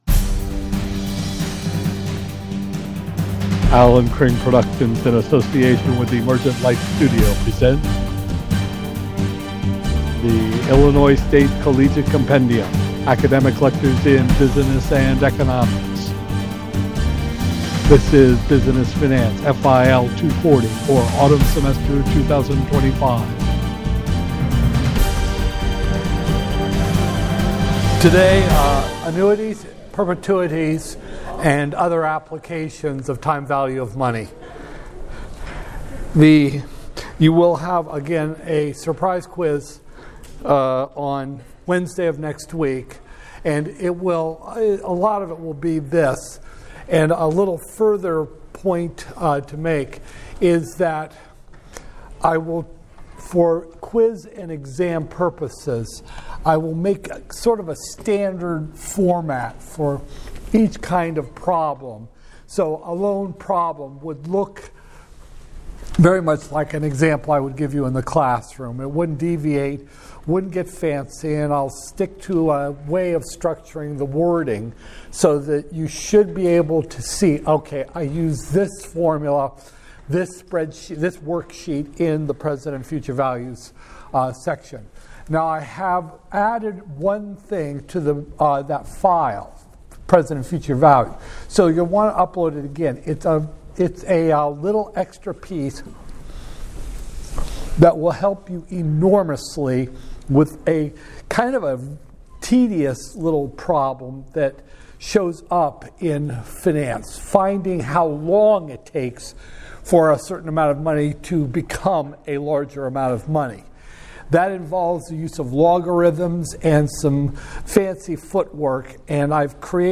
Business Finance, FIL 240-001, Spring 2025, Lecture 9